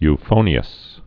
(y-fōnē-əs)